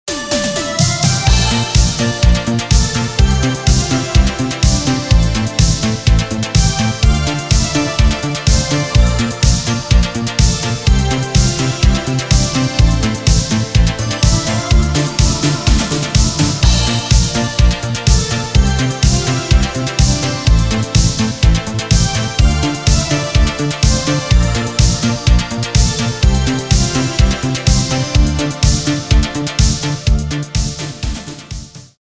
synthwave
synth pop
танцевальные